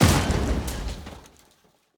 car-stone-impact-4.ogg